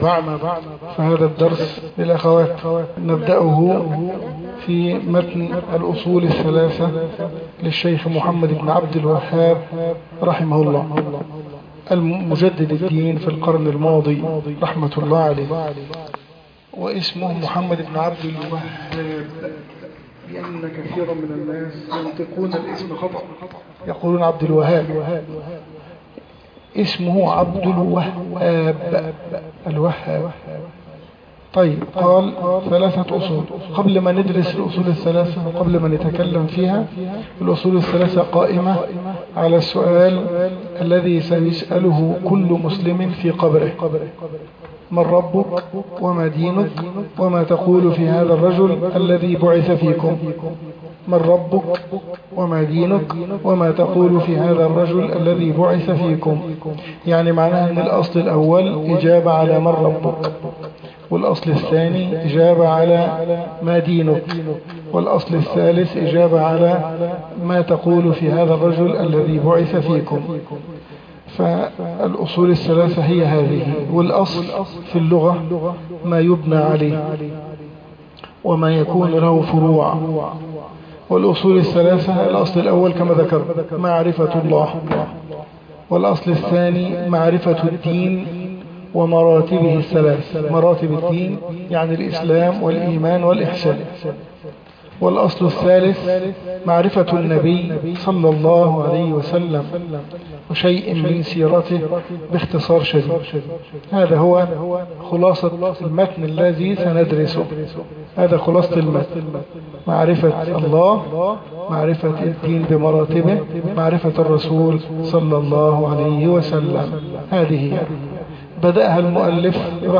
شرح ثلاثة الأصول وأدلتها الدرس 1